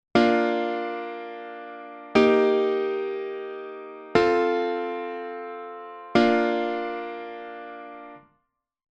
安定した響きのトニックであるIからはじまり、ドミナントに進む性格を持つサブドミナントのIV、そして不安定で緊張感のあるVを経過して安定したIに戻る、という展開です。
B♭→E♭→F→B♭（1→4→5→1）